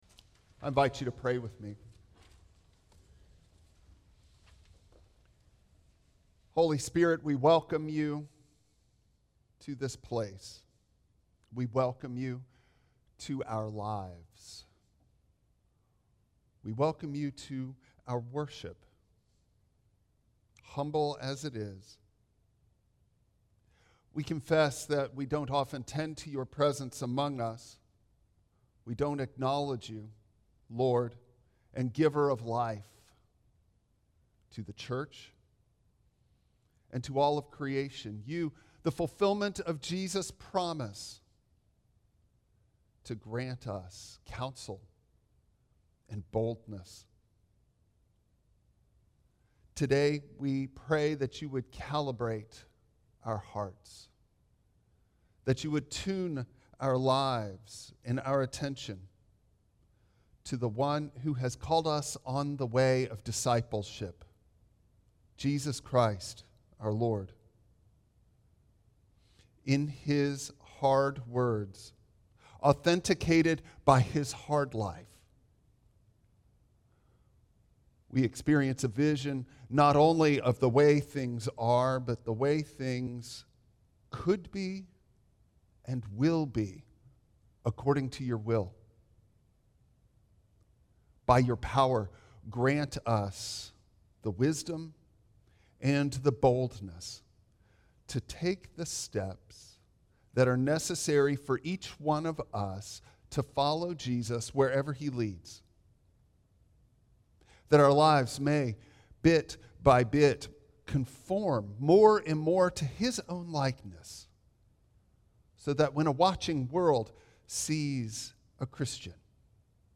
Luke 6:17-26 Service Type: Traditional Service Bible Text